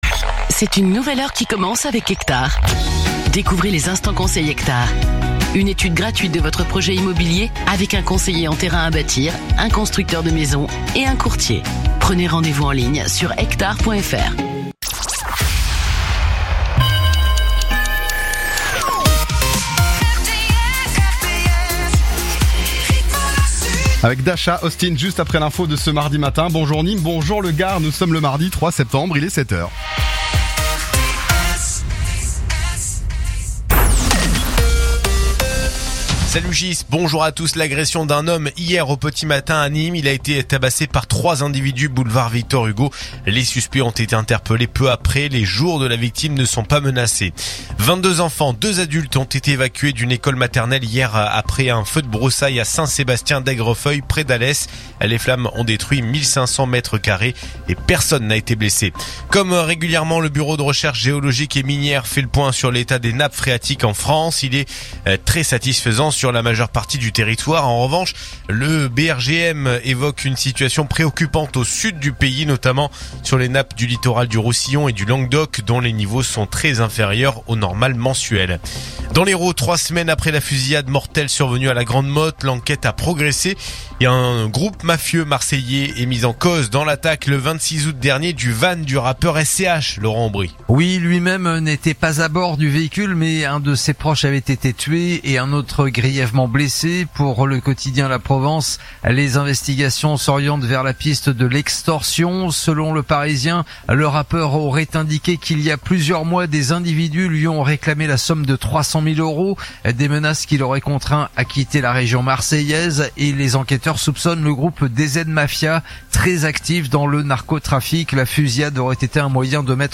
info_nimes_144.mp3